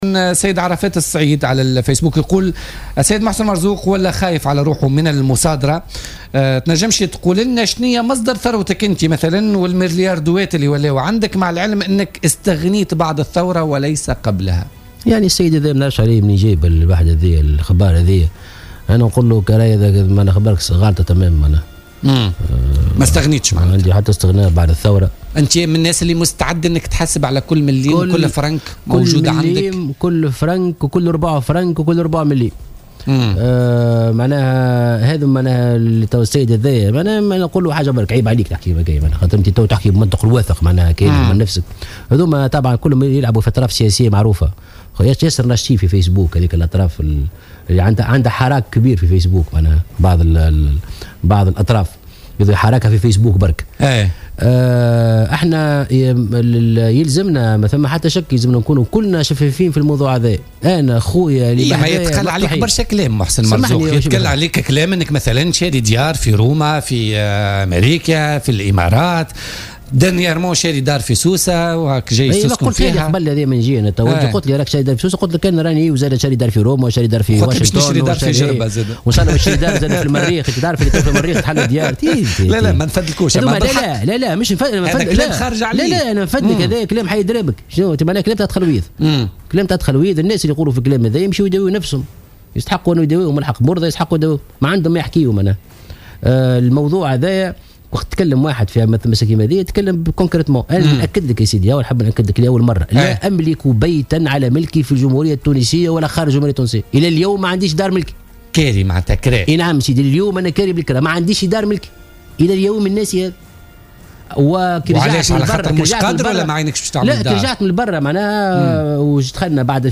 وتابع ضيف "بوليتيكا" في "الجوهرة أف أم" أنه إلى اليوم يسكن بيتا على وجه الكراء مؤكدا أنه لا يملك أي عقار باسمه باستثناء عقار امتلكه هدية من صهره بعد 2011.